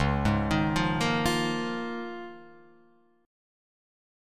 C#6add9 chord